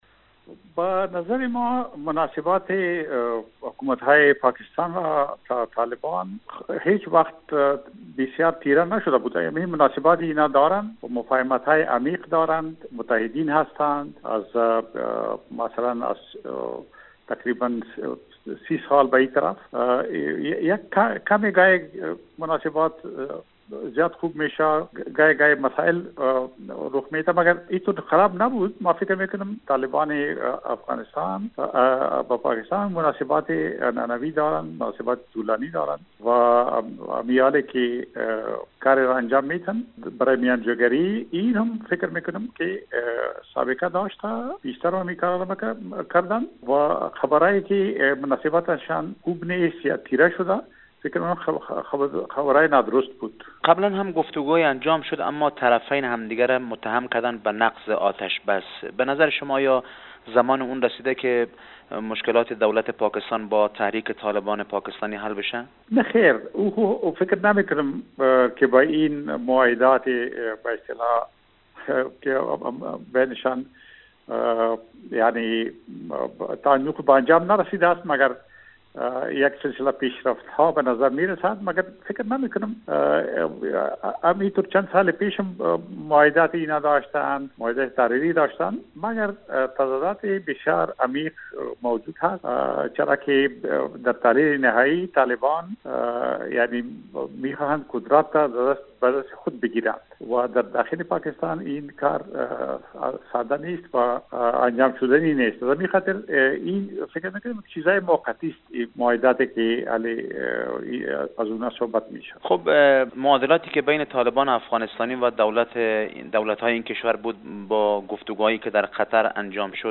افراسیاب ختک در گفت و گو با بخش خبر رادیو دری اظهار داشت: تفکیک نام طالبان افغانستان و طالبان پاکستان فقط برای تقسیم کار است و تمام آنان از سوی طالبان افغانستان رهبری می شوند.